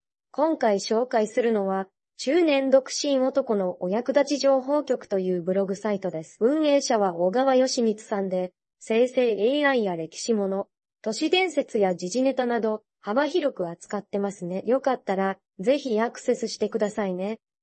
音声生成
言語、スピードを選んで、生成します。
出来た音声がこちらです。
まずまずといったところでしょうか。
ai_voice.mp3